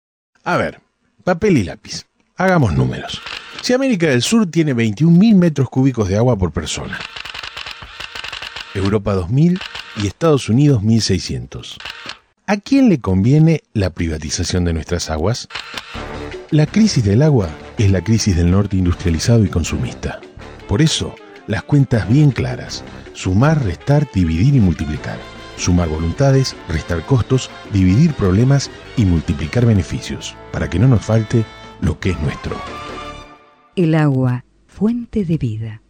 i) Spots radiales: A partir de la escucha de los audios radiales, utilizarlos como disparadores para generar investigaciones, escritos literarios o de no ficción.